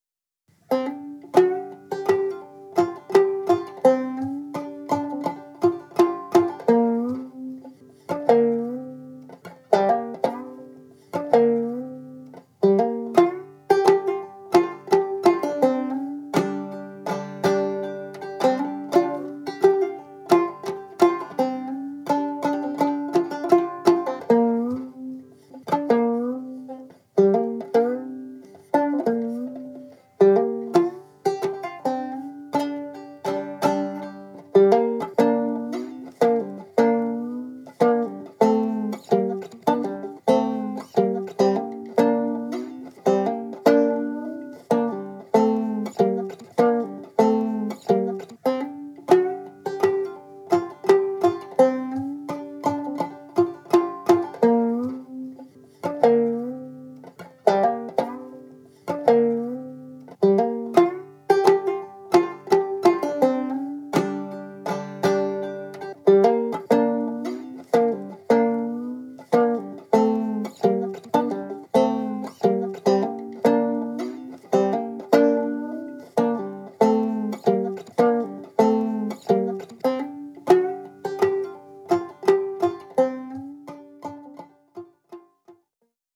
A solo that doesn’t have lyrics but does have a vocal part.
So I briefly grunted.